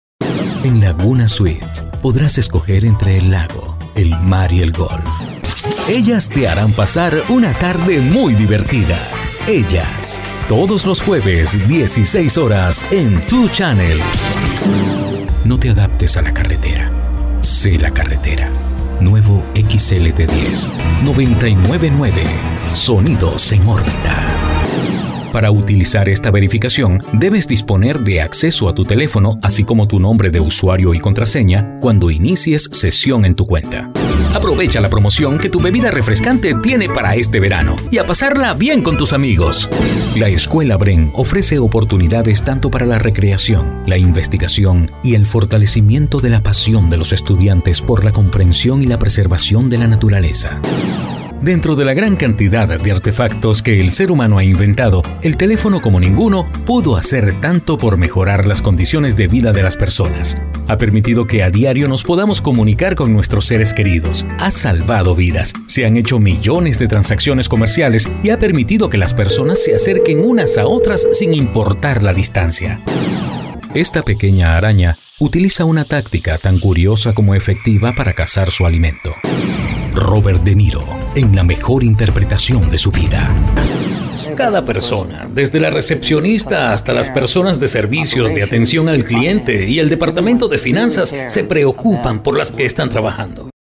Locutor profesional venezolano con manejo de acento neutro del español latinoamericano, voice over talent
Sprechprobe: Werbung (Muttersprache):